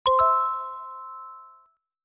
button-ok.mp3